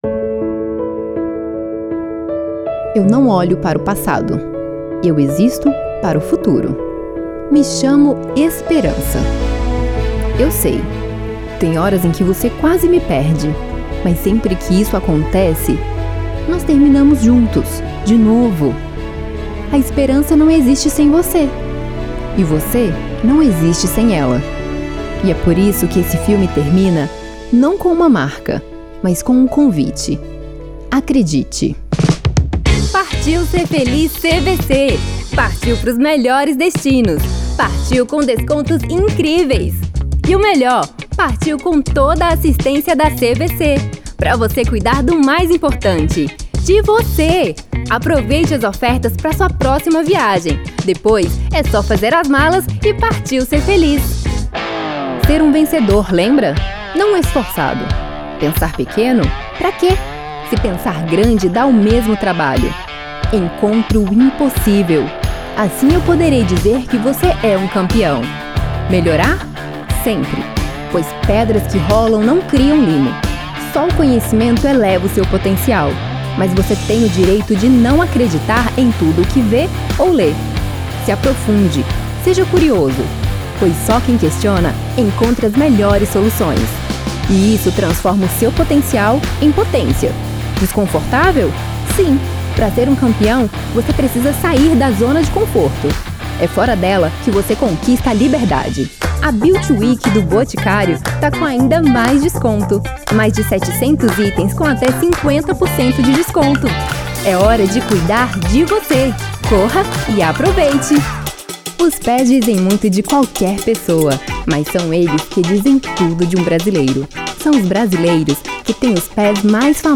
Spot Comercial
Vinhetas
VT Comercial
Espera Telefônica
Impacto
Animada
Caricata